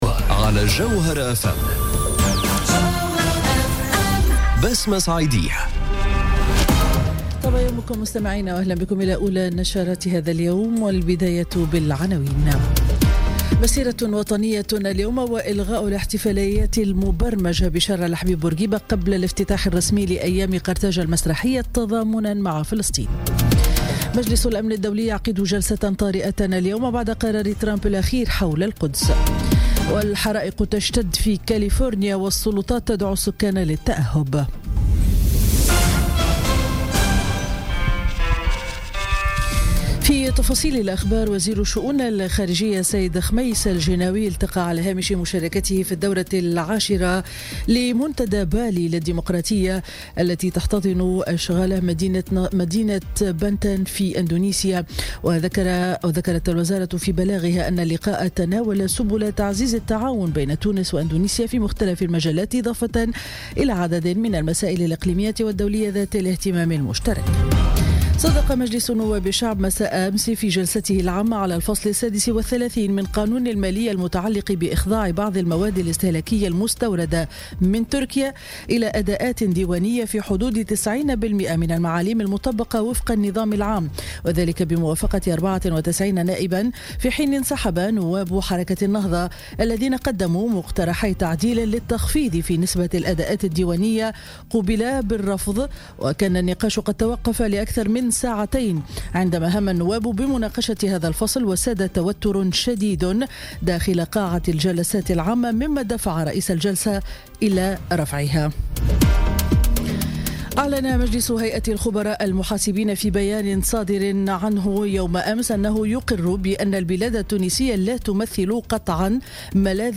Journal Info 07h00 du vendredi 08 décembre 2017